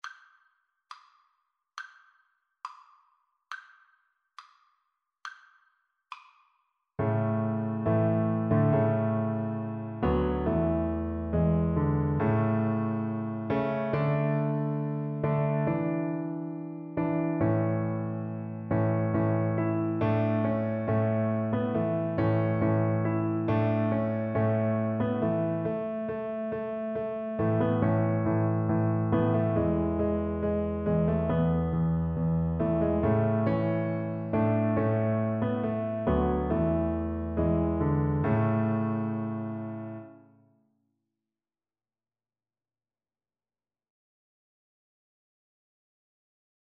Play (or use space bar on your keyboard) Pause Music Playalong - Piano Accompaniment Playalong Band Accompaniment not yet available transpose reset tempo print settings full screen
Double Bass
"Joy to the World" is a popular Christmas carol.
2/4 (View more 2/4 Music)
A major (Sounding Pitch) (View more A major Music for Double Bass )
Classical (View more Classical Double Bass Music)